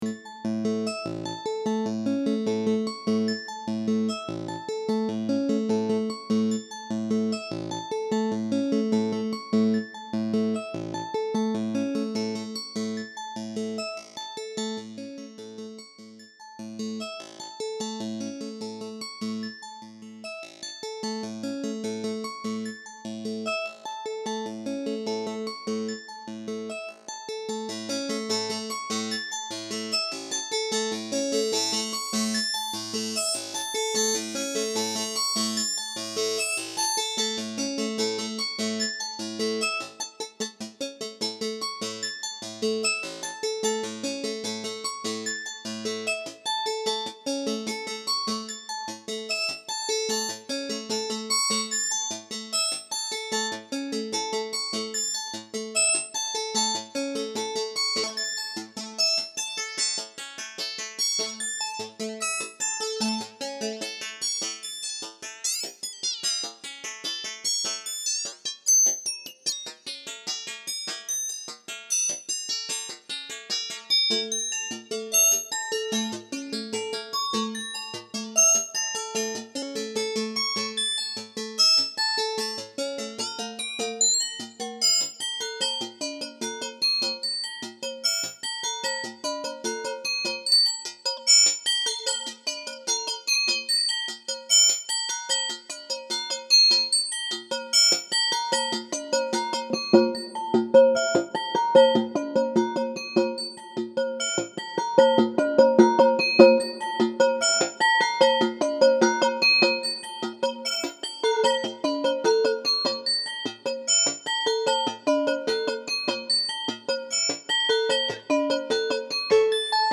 SOUND very experimental comlex evolving Leads and Basses, strange and new sounds, some plastic shimmering also in it..
physical modeling sound demo 1
Kleine Demo der Physical Modeling Möglichkeiten zum Special im Synthesizer-Magazin 18